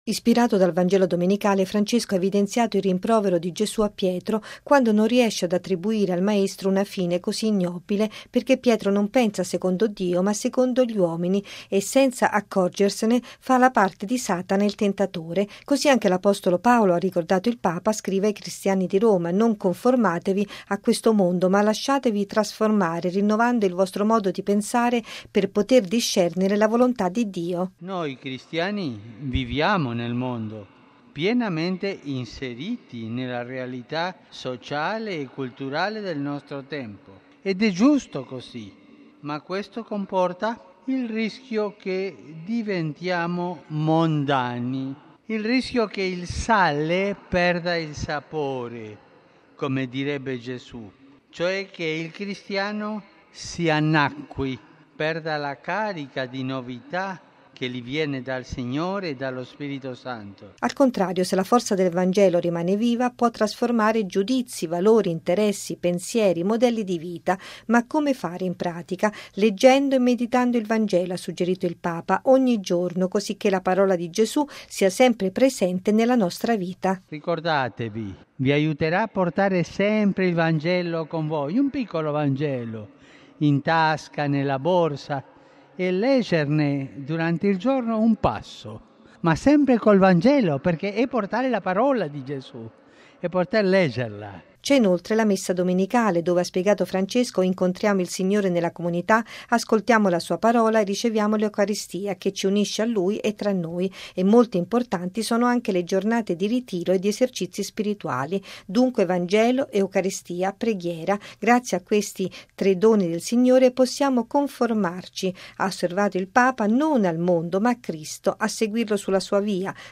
Francesco all’Angelus: dominare con forza del Vangelo i rischi di mondanità